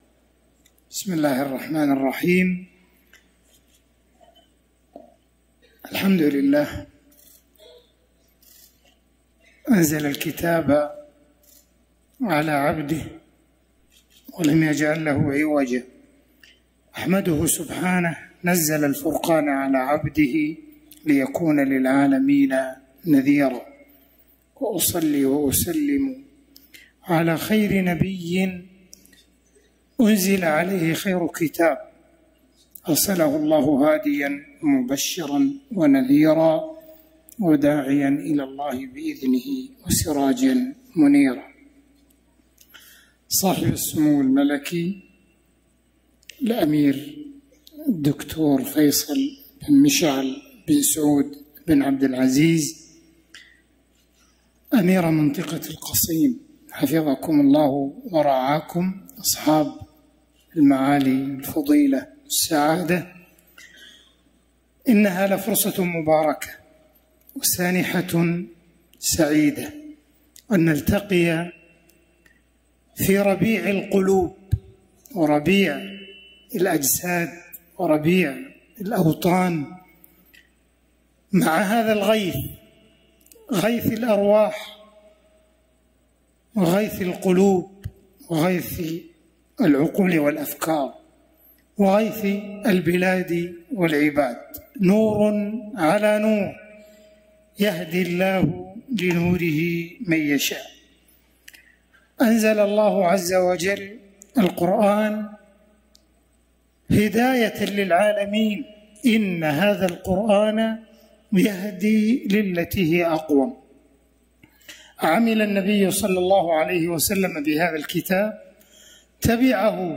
كلمة معالي الشيخ عبدالرحمن السديس في حفل تخريج الدفعة الخامسة عشر بجمعية تحفيظ الرس